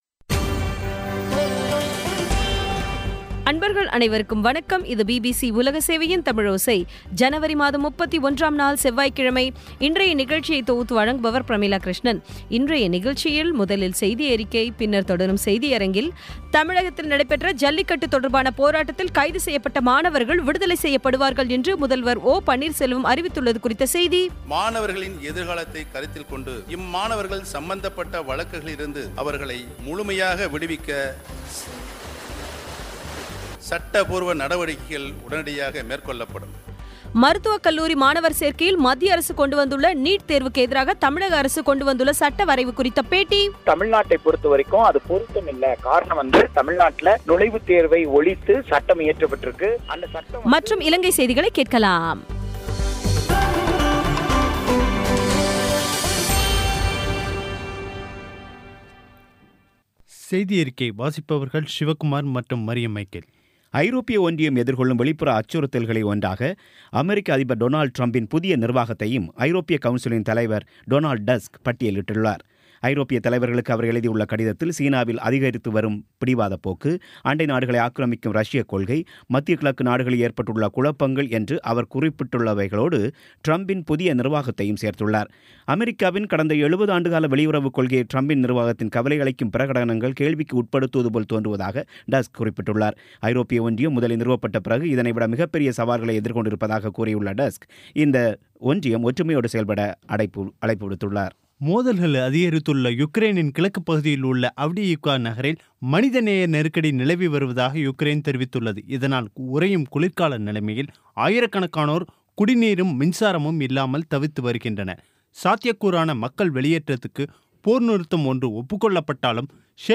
தமிழகத்தில் நடைபெற்ற ஜல்லிக்கட்டு தொடர்பான போராட்டத்தில் கைது செய்யப்பட்ட மாணவர்கள் விடுதலை செய்யப்படுவார்கள் என்று முதல்வர் ஓ.பன்னீர்செல்வம் அறிவித்துள்ளது குறித்த செய்தி மருத்துவக் கல்லூரி மாணவர் சேர்க்கையில் மத்திய அரசு கொண்டுவந்துள்ள 'நீட்' தேர்வுக்கு எதிராக தமிழக அரசு கொண்டுவந்துள்ள சட்ட வரைவு குறித்த பேட்டி ஆகியவை கேட்கலாம்